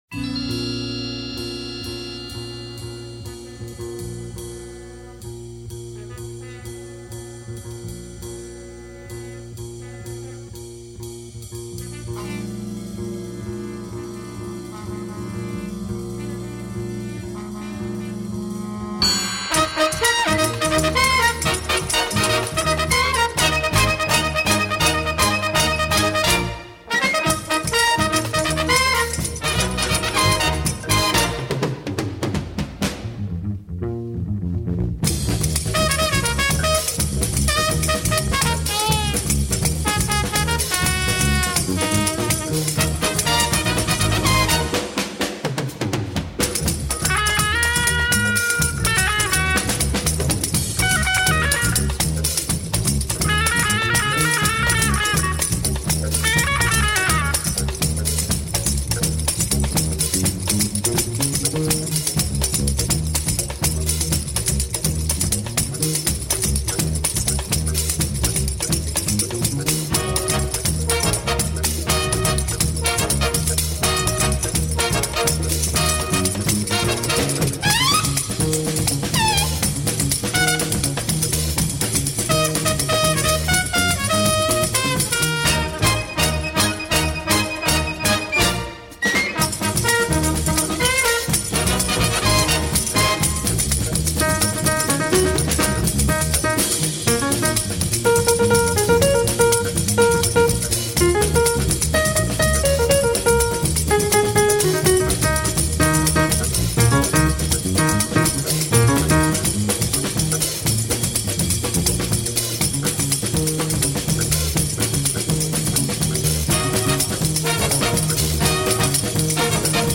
et des munificences blues-funk